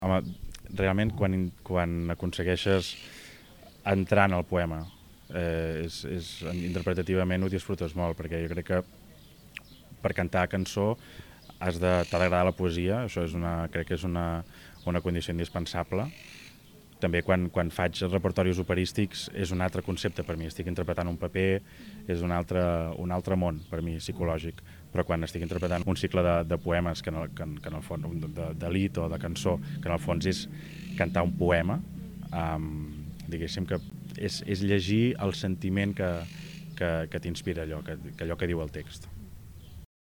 Van actuar el divendres 15 al Museu de la Mediterrània, amb un repertori molt influenciat per la poesia.